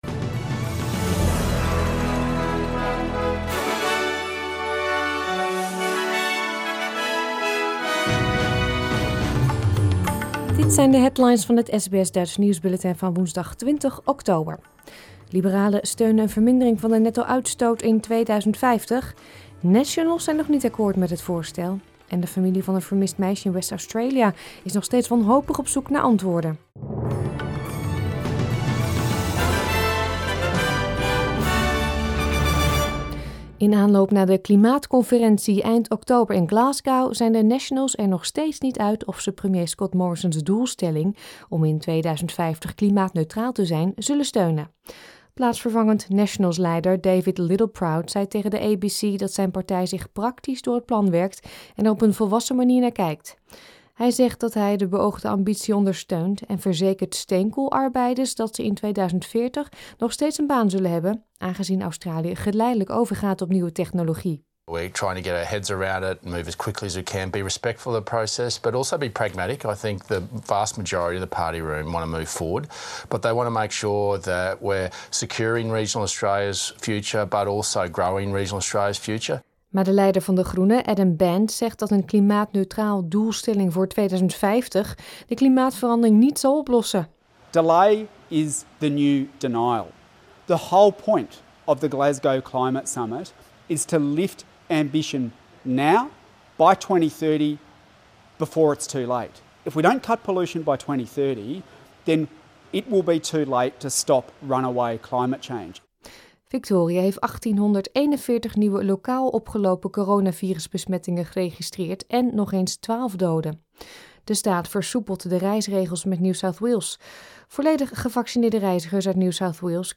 Nederlands/Australisch SBS Dutch nieuwsbulletin van woensdag 20 oktober 2021